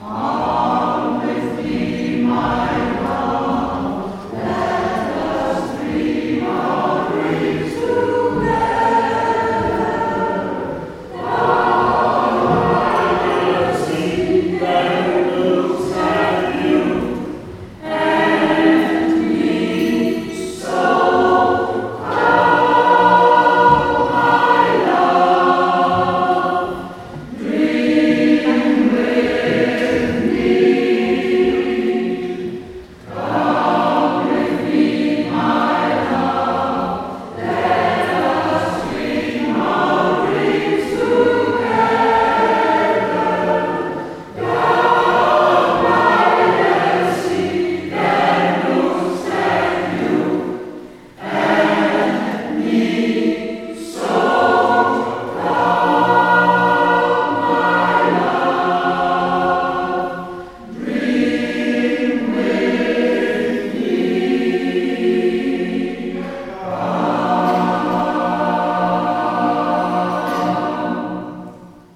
Koorweekend op Texel
Met op zaterdag 31 augustus om 20.00 uur een concertje in het kerkje in Hoorn.
Mp3-tjes van het optreden: